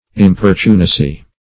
Meaning of importunacy. importunacy synonyms, pronunciation, spelling and more from Free Dictionary.
Search Result for " importunacy" : The Collaborative International Dictionary of English v.0.48: Importunacy \Im*por"tu*na*cy\, n. [From Importunate .]